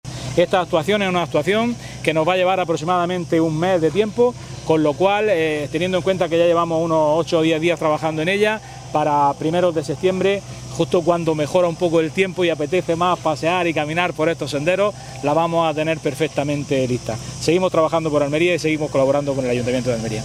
ANTONIO-JESUS-RODRIGUEZ-DIPUTADO-FOMENTO-E-INFRAESTRUCTURAS.mp3